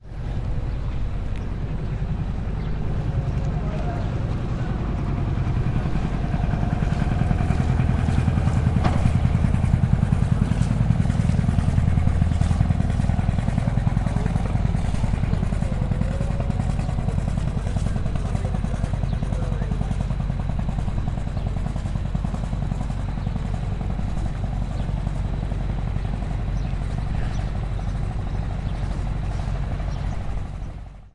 船体上的波浪和声音有时也可以听到。
Tag: 柴油 船舶 汽车 海洋 现场录音 发动机